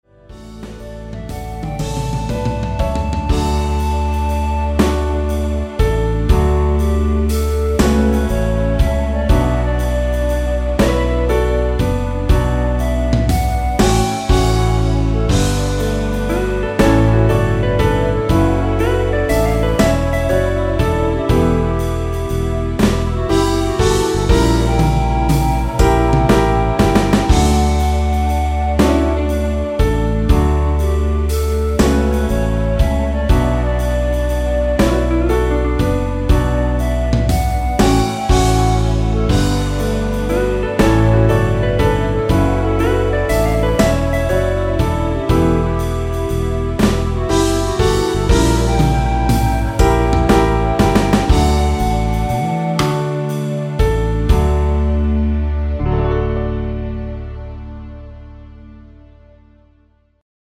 엔딩이 페이드 아웃이라 가사 끝 (널 사랑해) 까지 하고 엔딩을 만들었습니다.(가사및 미리듣기 참조)
앞부분30초, 뒷부분30초씩 편집해서 올려 드리고 있습니다.
중간에 음이 끈어지고 다시 나오는 이유는